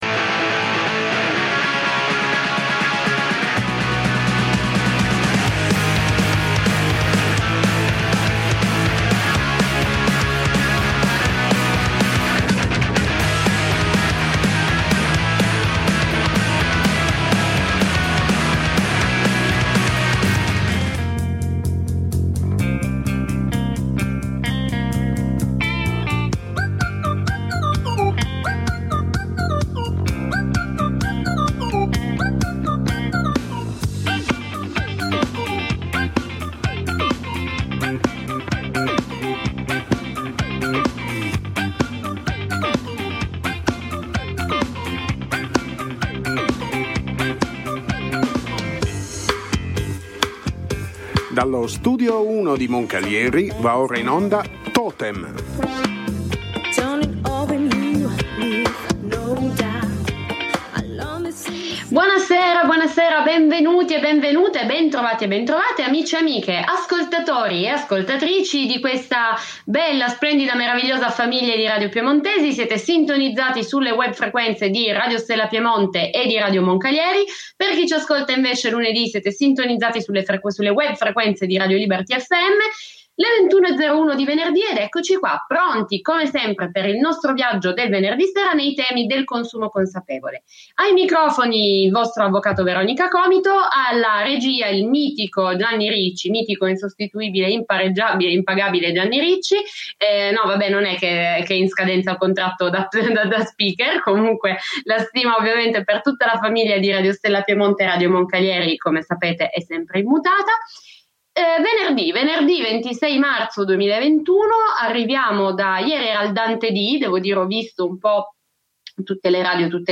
Parliamo di TAEG e di consumo consapevole a “TOTEM“, trasmissione radiofonica in onda su Radio Stella Piemonte e Radio Moncalieri.